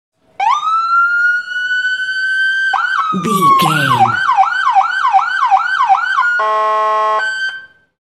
Police Car Large Short Siren Horn Combo
Sound Effects
chaotic
anxious
emergency